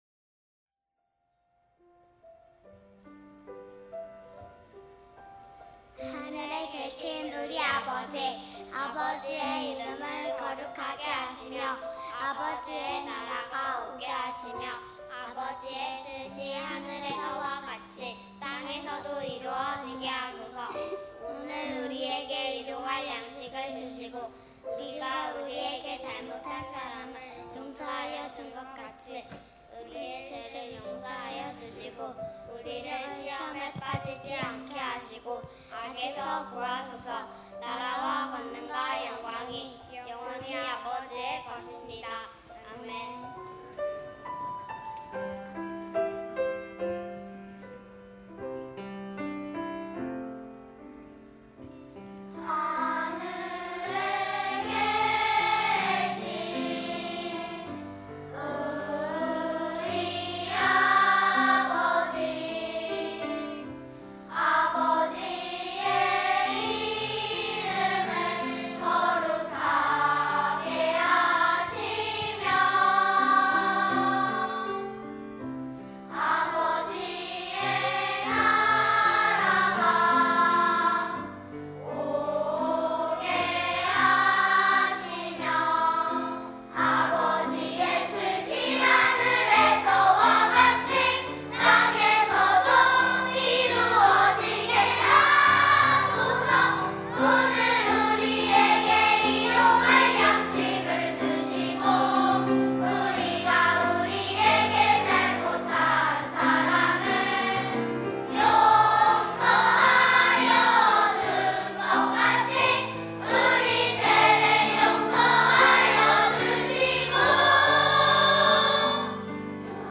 # 첨부 1.01 주기도송(아동부 연합찬양대).mp3